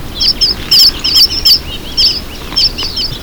Red Avadavat
Amandava amandava
Also called Strawberry Finch and Red Munia.